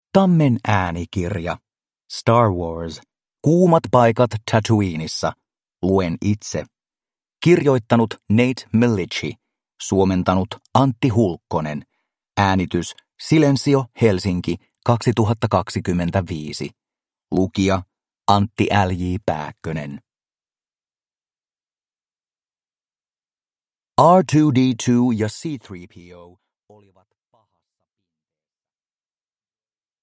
Star Wars. Kuumat paikat Tatooinessa. Luen itse. (ljudbok) av Star Wars